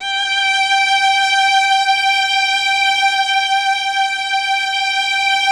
MELLOTRON.14.wav